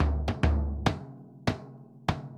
Bombo_Baion_100_1.wav